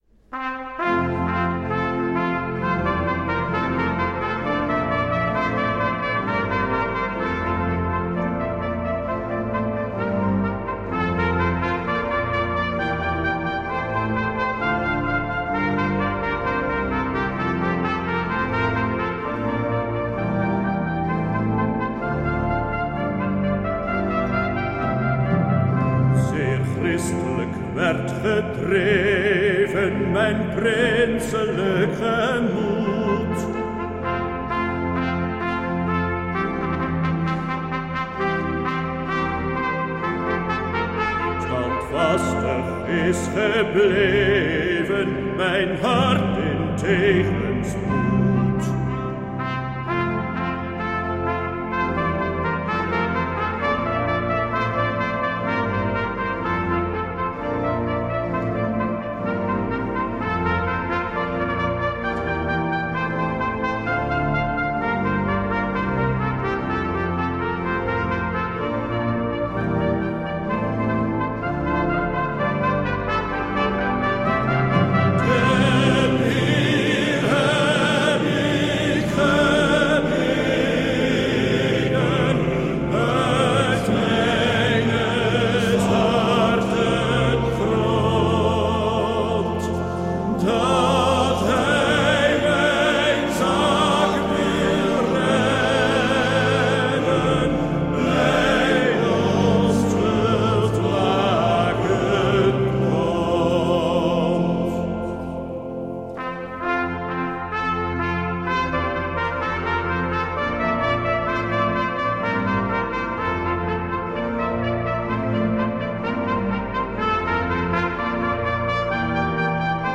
Uitvoering van “Wilhelmus-cantate”  op 30 april 2016
Bevrijdingsconcert - Wilhelmus
Wilhelmus vers 13 (Wilhelmuscantate – Urkermannenkoor)